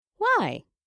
알림음 8_WomanAh2.mp3